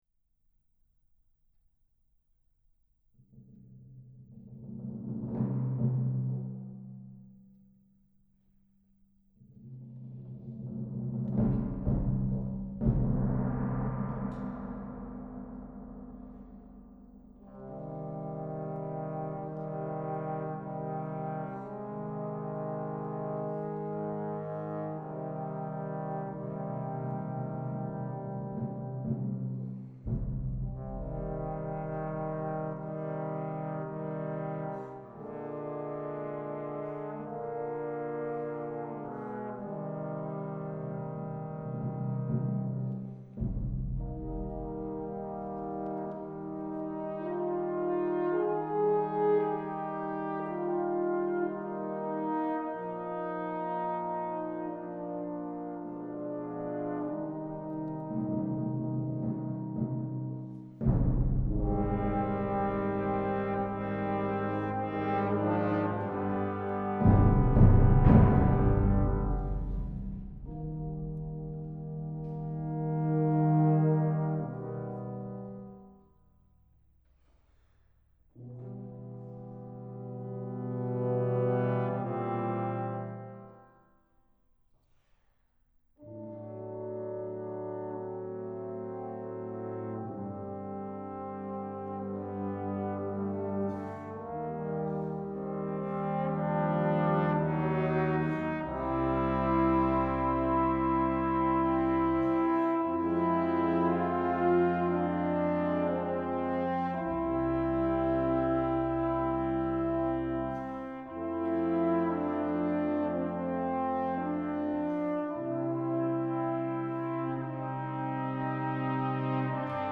concert piece
begins with a short equale in the low brass before moving through various woodwind and brass textures that depict a funeral procession and the mixed emotions surrounding loss and grief.